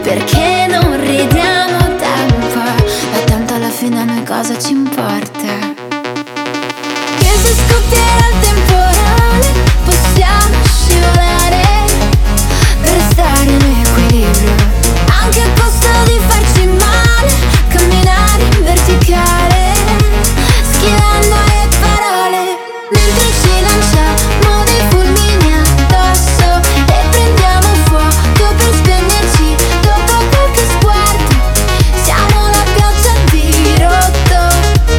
Жанр: Поп / Русские
# Pop